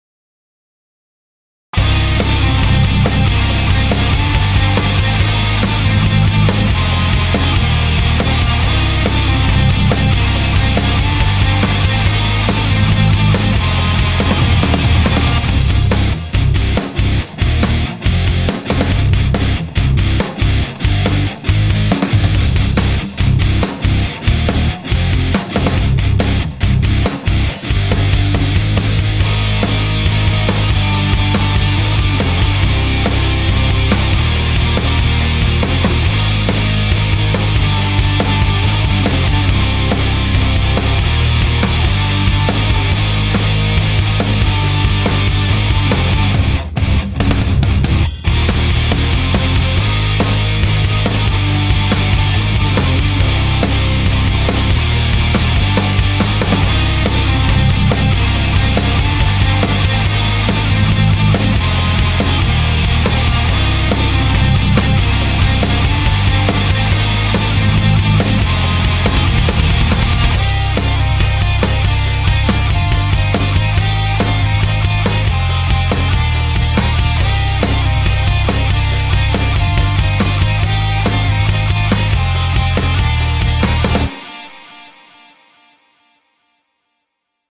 Вниз  Играем на гитаре